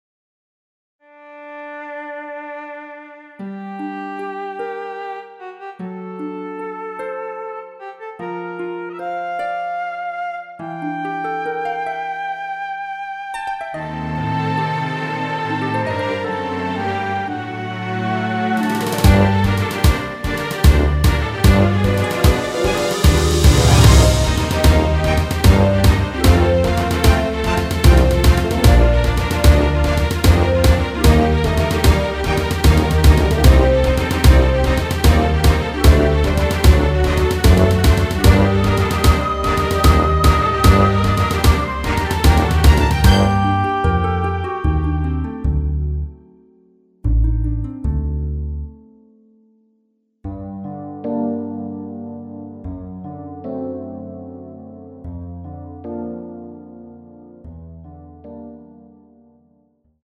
Gm
◈ 곡명 옆 (-1)은 반음 내림, (+1)은 반음 올림 입니다.
앞부분30초, 뒷부분30초씩 편집해서 올려 드리고 있습니다.
중간에 음이 끈어지고 다시 나오는 이유는